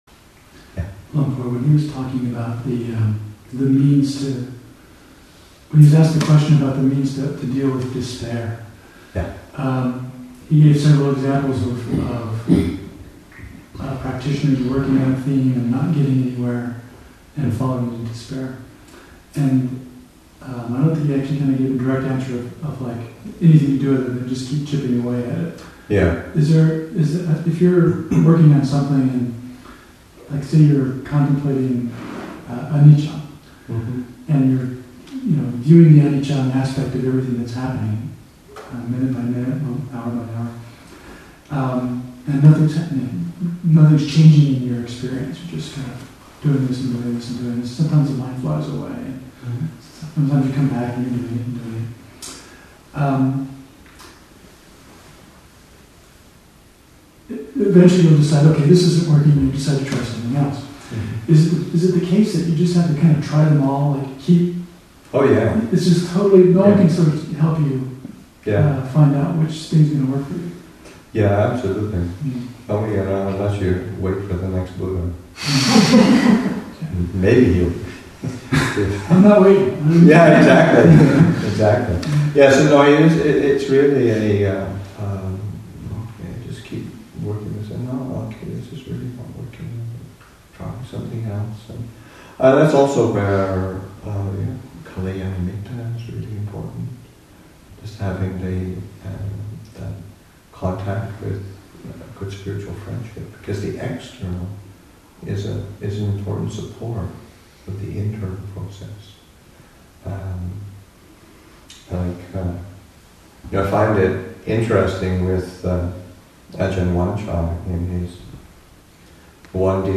Our Roots in the Thai Forest Tradition, Session 51 – Mar. 17, 2014